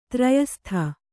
♪ trayastha